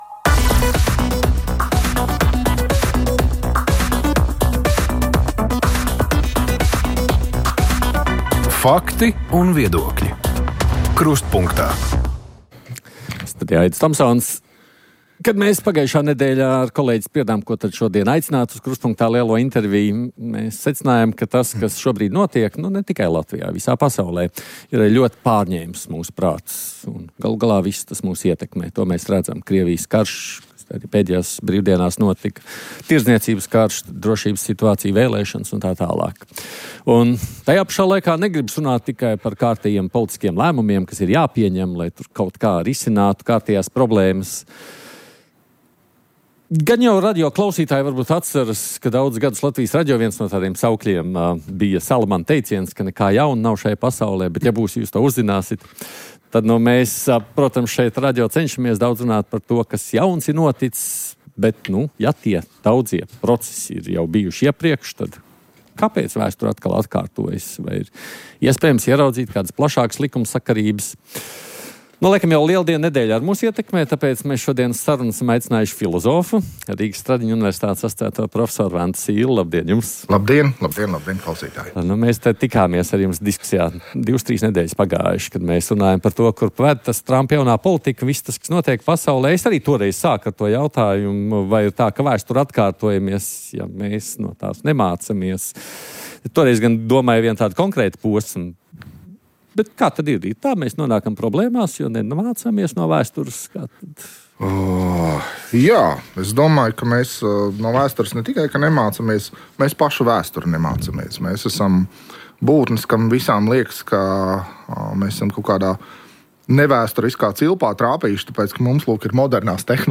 Diskutē Neatliekamās medicīniskās palīdzības dienesta vadītāja